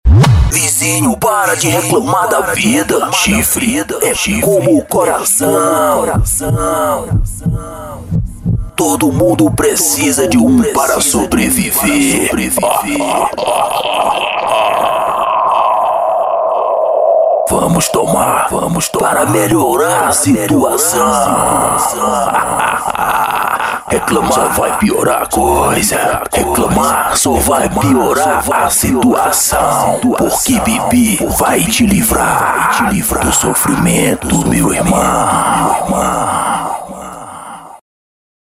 Humor,Sátiras,Comédia!